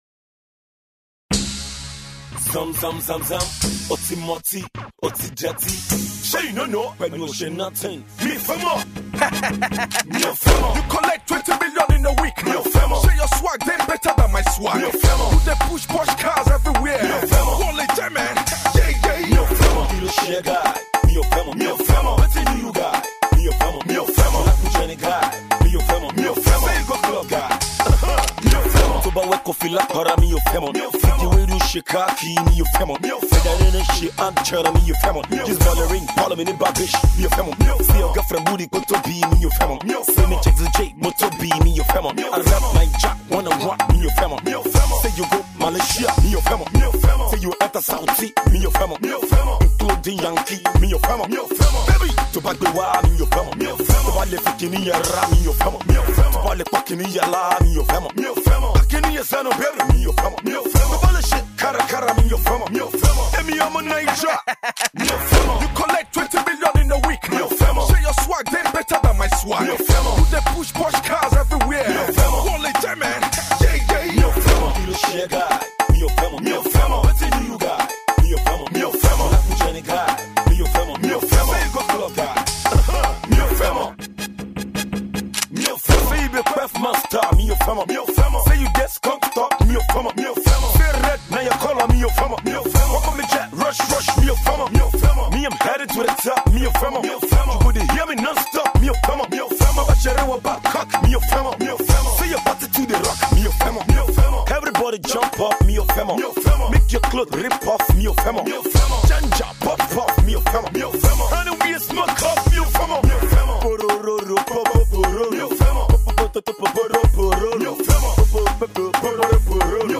Yoruba rap